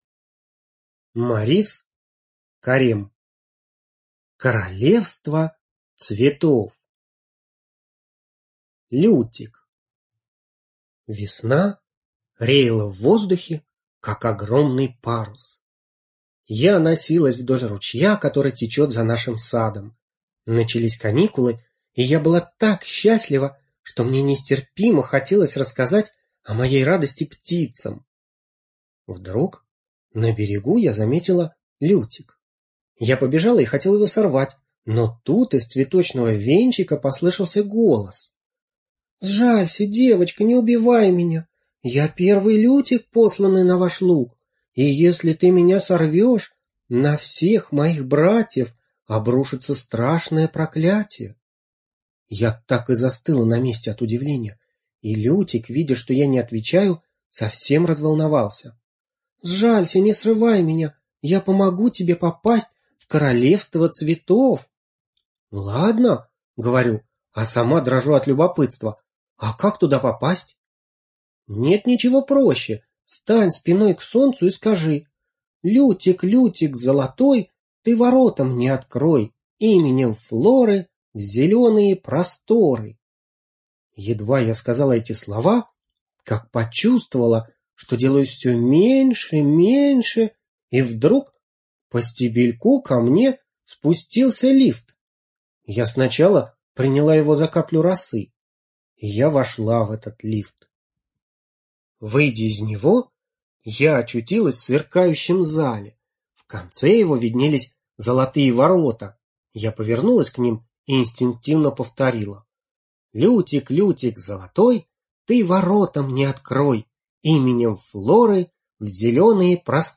Королевство цветов - аудиосказка Карема - слушать онлайн